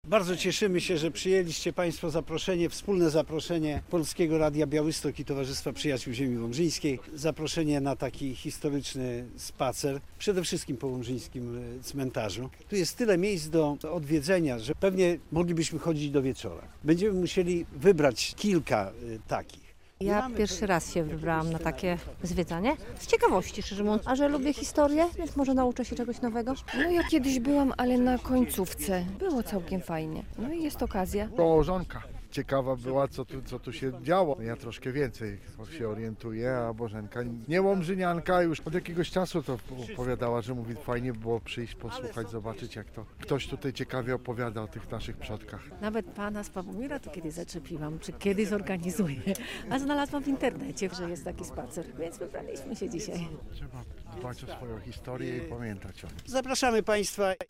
Słuchaj: Podsumowanie spaceru historycznego "Śladami pamięci" - relacja